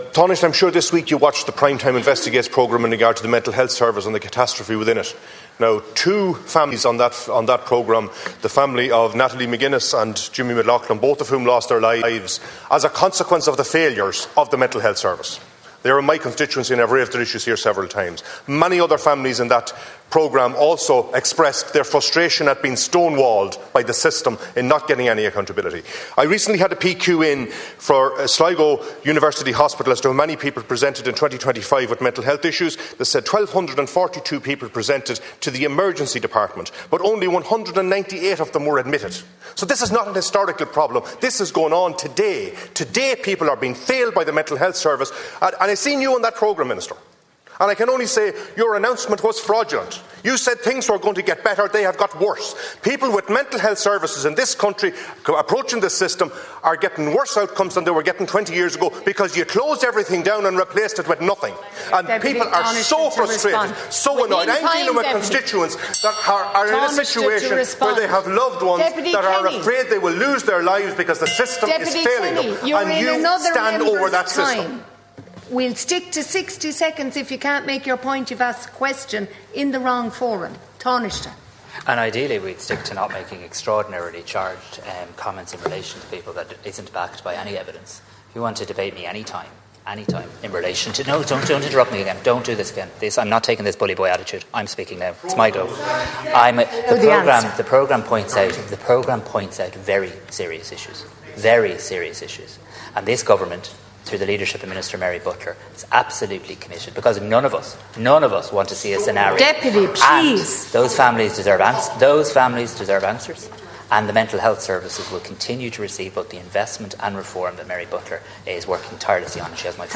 Tanaiste Simon Harris and Sligo Leitrim and South Donegal TD Martin Kenny clashed in the Dail this afternoon about an RTE Prime Time programme this week highlighting deficiencies in the mental health service.